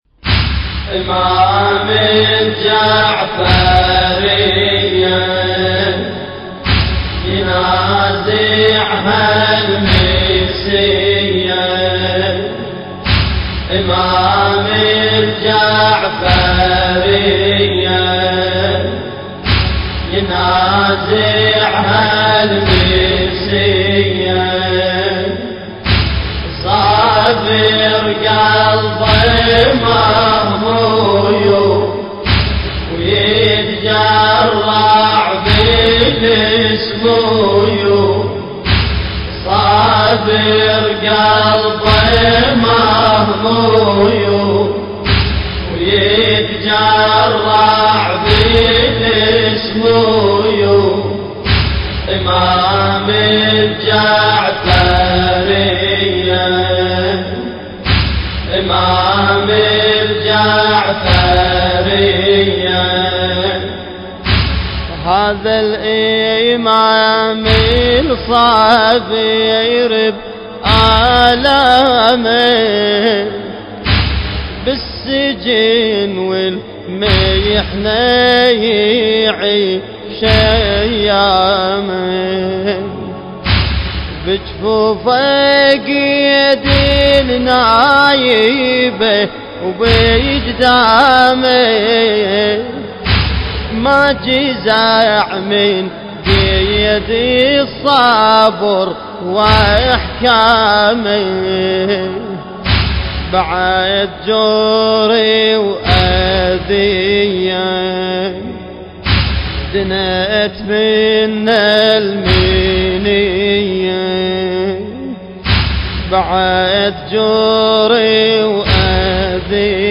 مراثي الامام الصادق (ع)